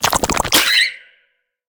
Sfx_creature_penguin_skweak_04.ogg